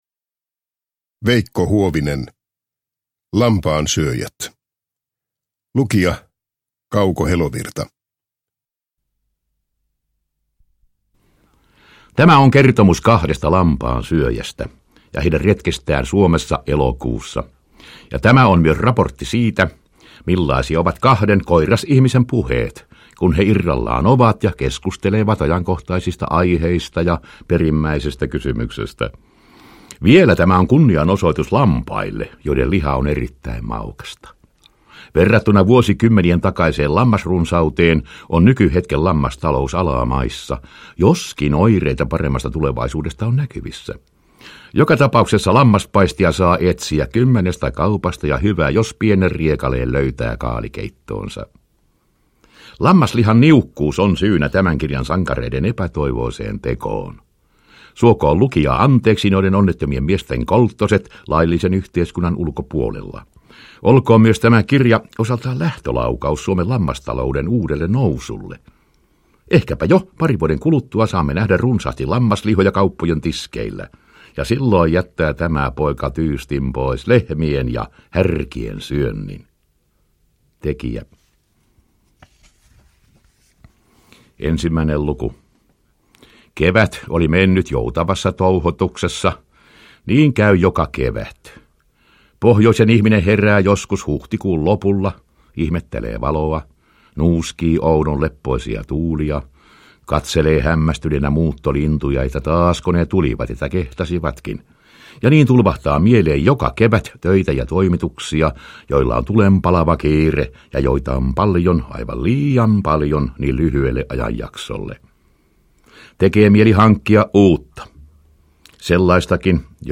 Lampaansyöjät – Ljudbok